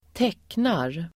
Uttal: [²t'ek:nar]